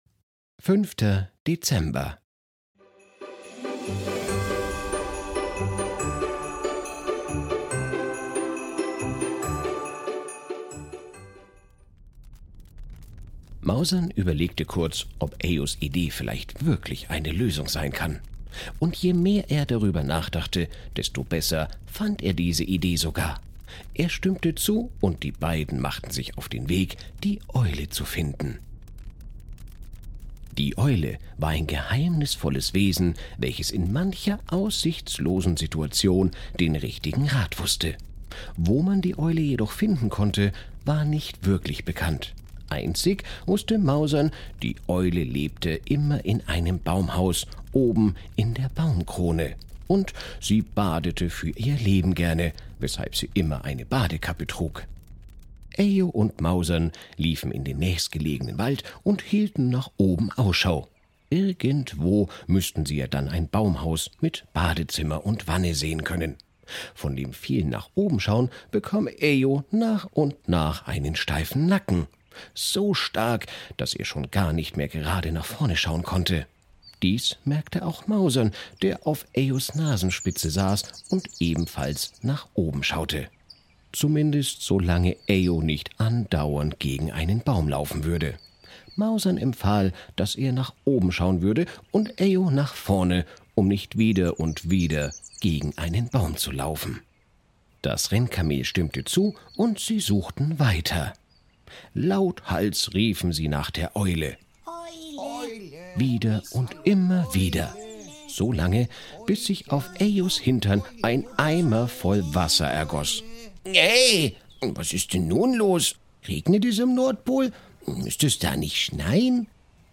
Ein Kinder Hörspiel Adventskalender